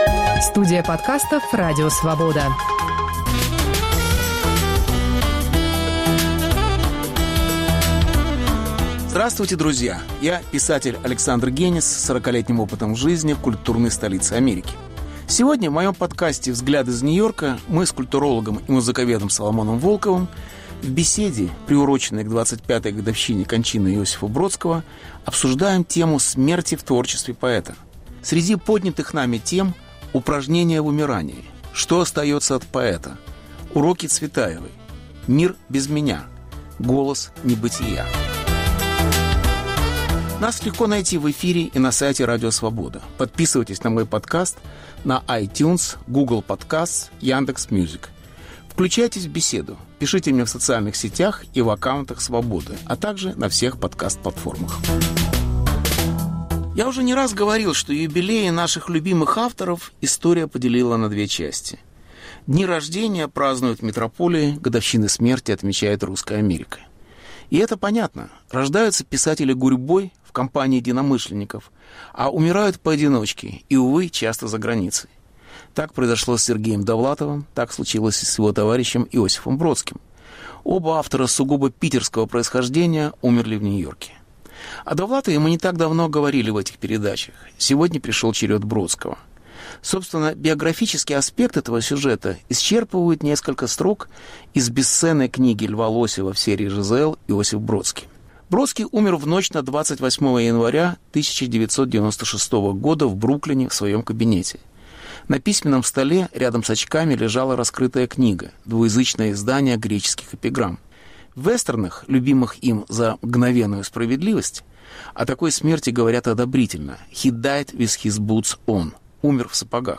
Беседа с Соломоном Волковым, приуроченная к 25-й годовщине кончины поэта.